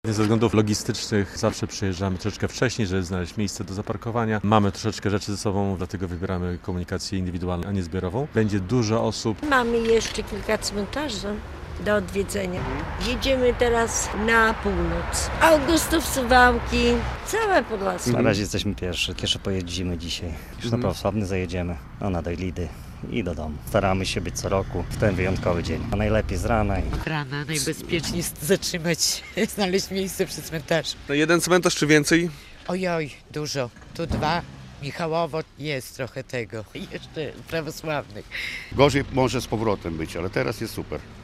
Podlasianie odwiedzają cmentarze w Święto Wszystkich Świętych - relacja